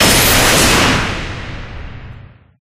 Collapse2.ogg